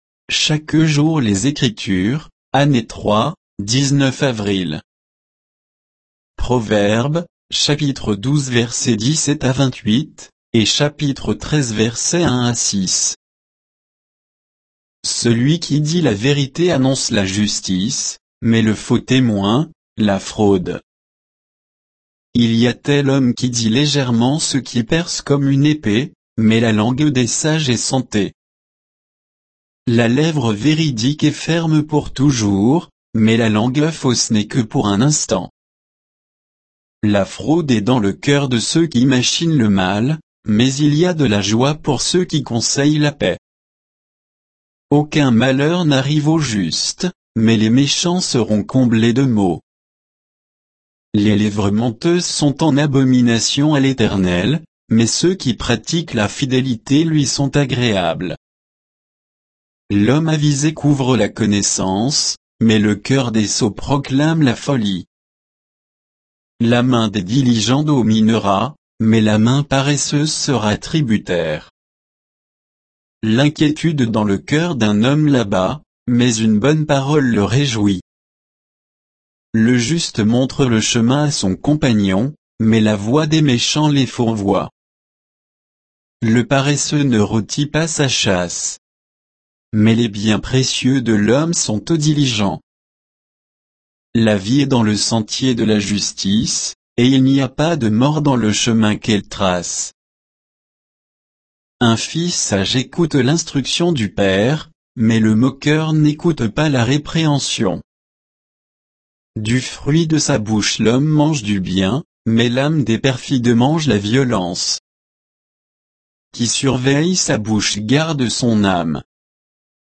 Méditation quoditienne de Chaque jour les Écritures sur Proverbes 12, 17 à 13, 6